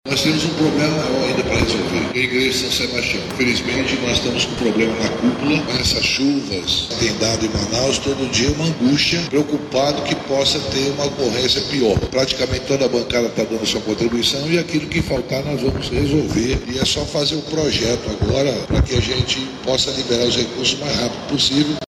O senador Omar Aziz, coordenador da bancada federal do Amazonas, também esteve presente na cerimônia e garantiu mais recursos para a segunda etapa de restauração da Igreja de São Sebastião.